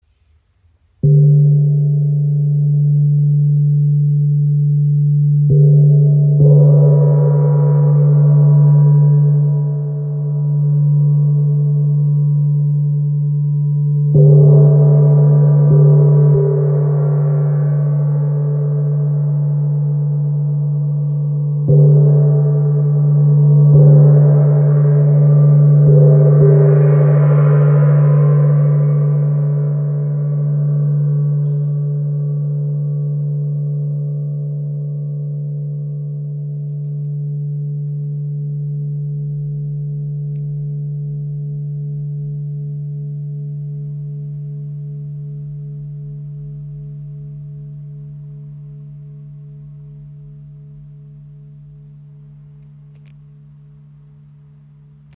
Chau Gong 50 cm | Tam-Tam Gong für Klangtherapie & Musik • Raven Spirit
Sanft in der Mitte anschlagen für tiefen, harmonischen Klang.
Klangbeispiel
Kraftvoller, harmonischer Klang mit langem Nachhall